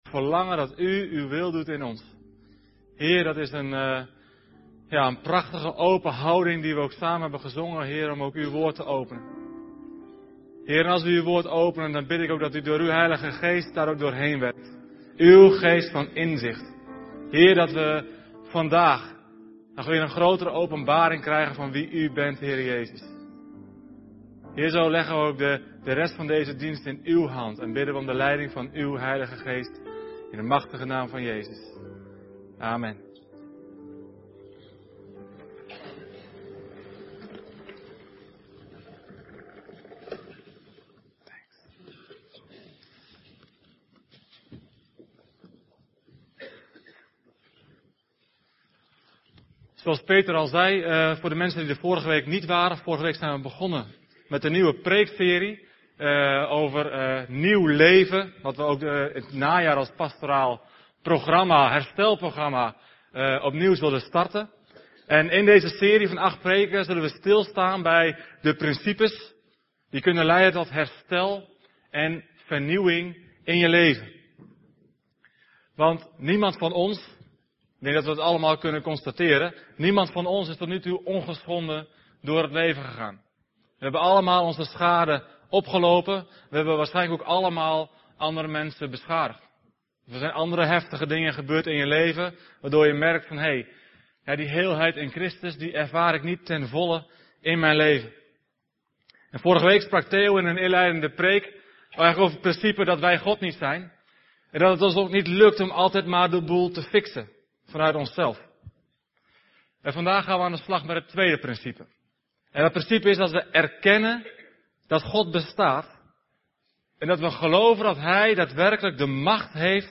Om ook de preken van de zondagse diensten te kunnen beluisteren op welk moment en waar je maar wilt, worden de preken ook als audio-uitzending gedeeld.